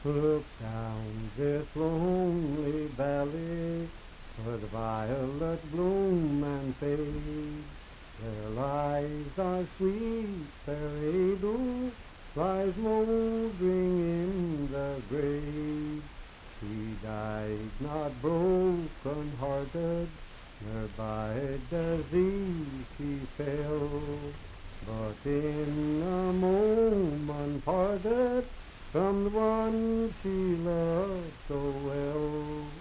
Unaccompanied vocal music
Verse-refrain 2(4).
Voice (sung)
Franklin (Pendleton County, W. Va.), Pendleton County (W. Va.)